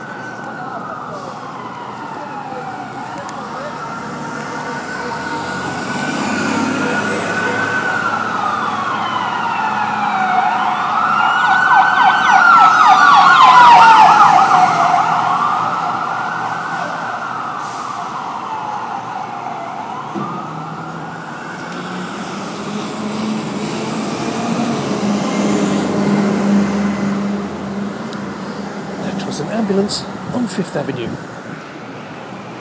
Ambulance on 5th Ave NYC